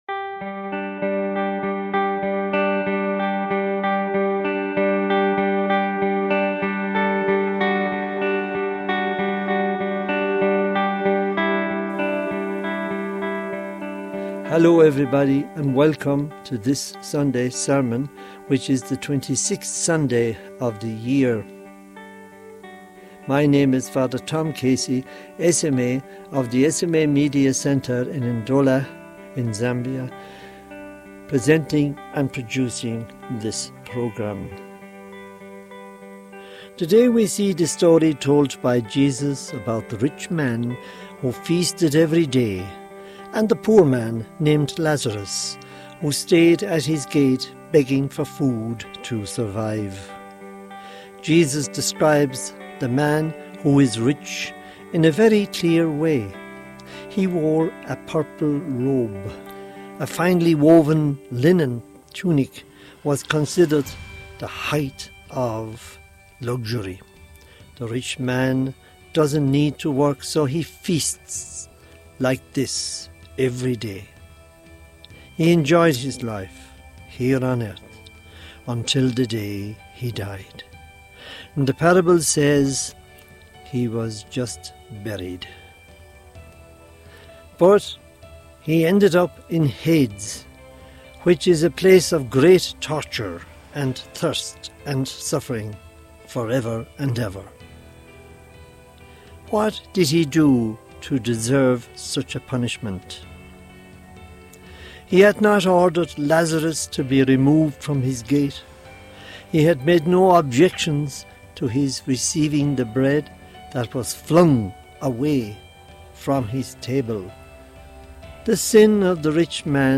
Homily for the 26th Sunday of Ordinary Time, 2025 | Society of African Missions